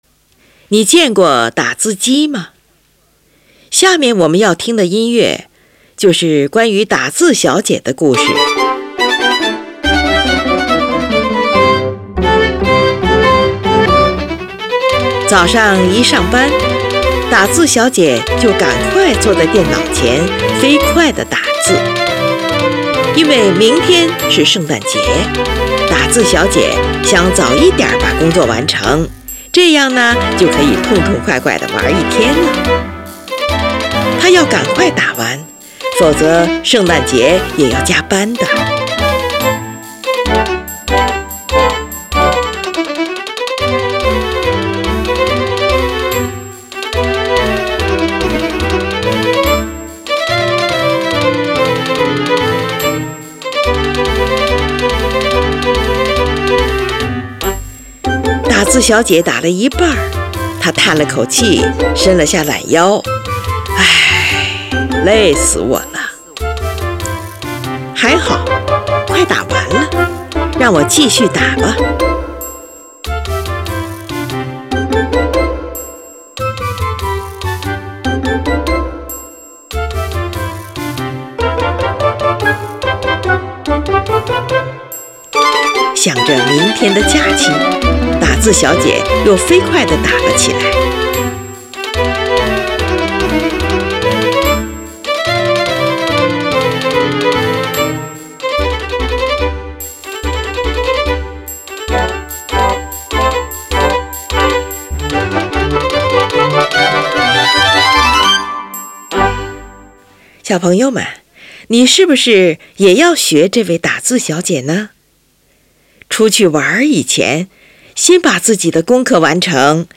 作于1950年，原为管弦乐曲，这里选用的是轻音乐曲。
在原乐曲中，作者独具匠心地用真正的打字机作为节奏乐器，描绘了大城市办公大楼中喧嚣的打字机声和繁忙的办公场面。